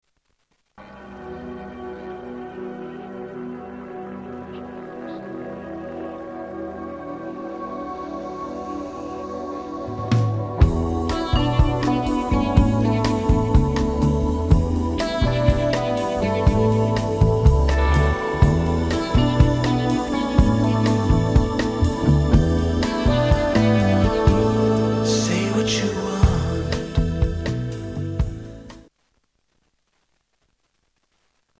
STYLE: Pop
At the moody pop end of things